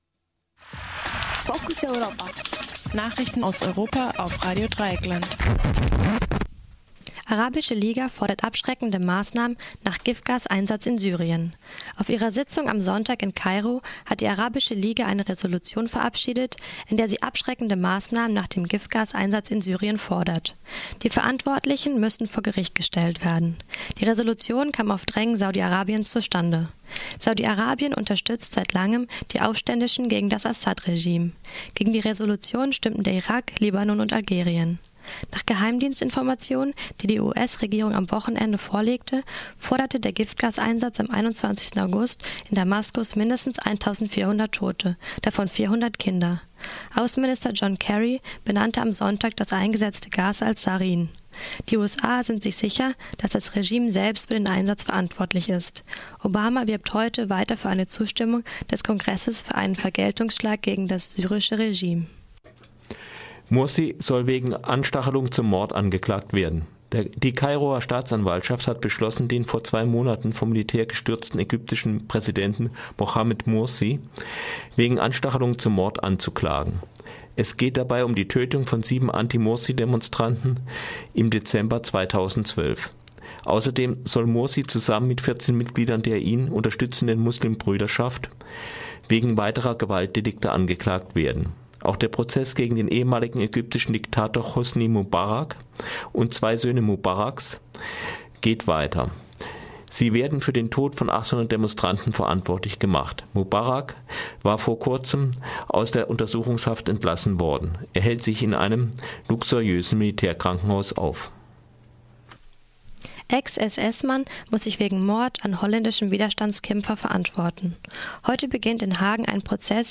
Focus Europa Nachrichten vom Montag, den 2. September - 12:30